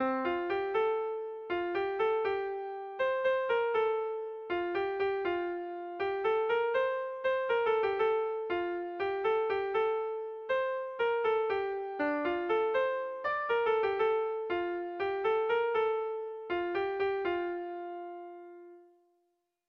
ABDE